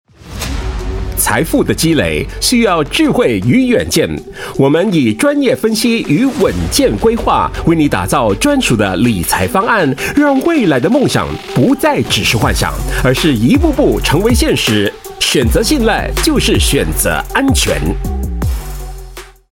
Voice Samples: Financial
male